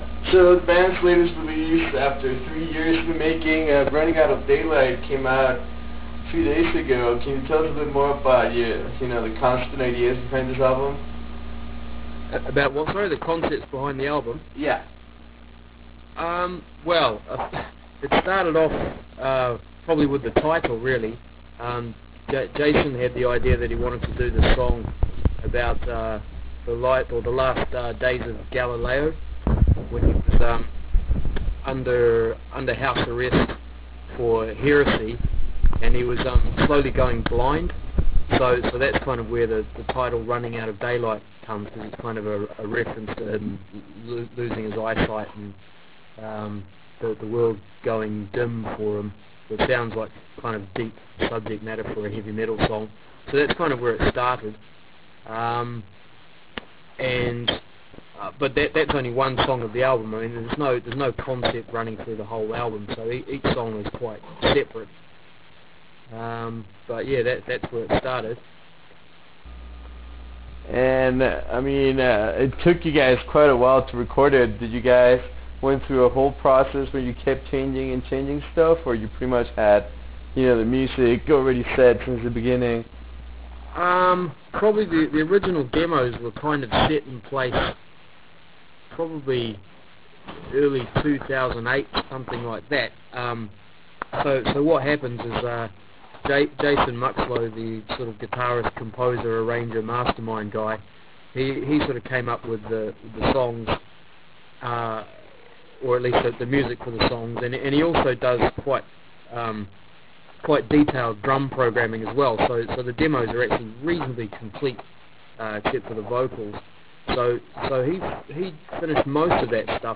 We also discuss what is in store for band in the future and how are they going to promote this release. To listen to this 20+ minute interview, please click HERE or Right Click and select Save As to take this interview with you.